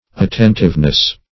attentiveness \at*ten"tive*ness\ n.